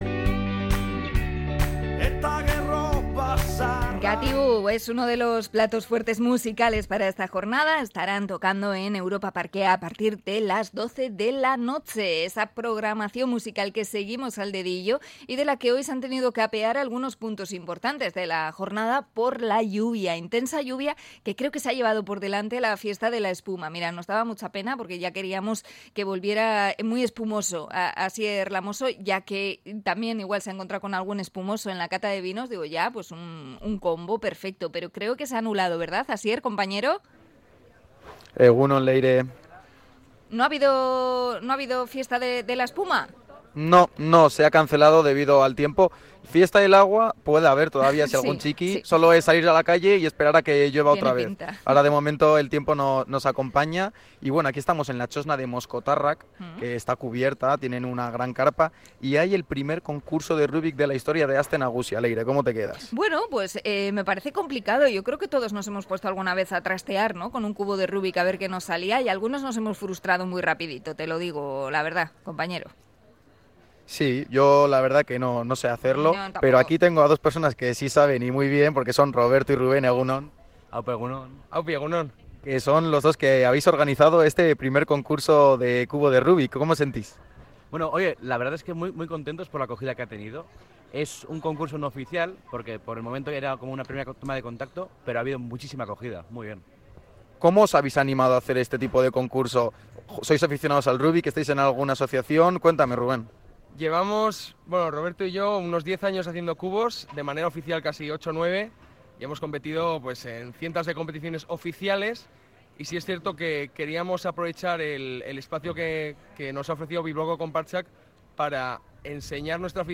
Conexión desde Moskotarrak en el I Campeonato de Rubik de Aste Nagusia